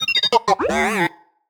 happy1.ogg